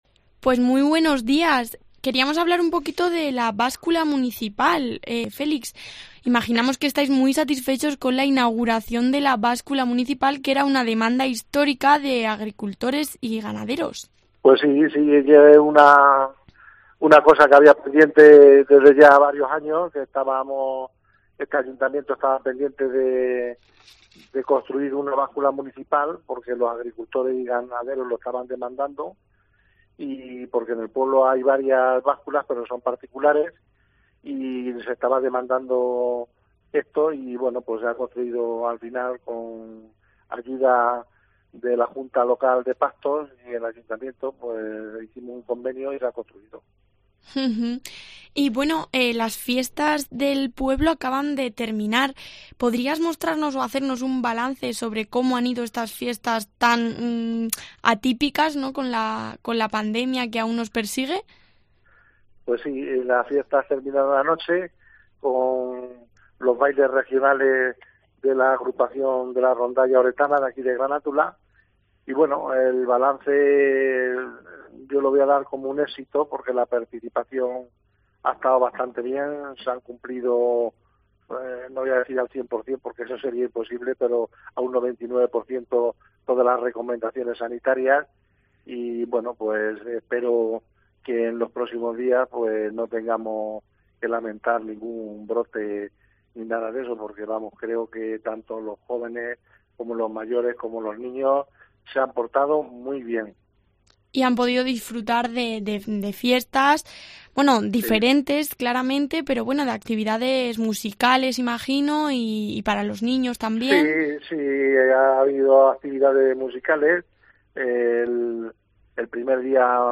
Entrevista alcalde Granátula de Calatrava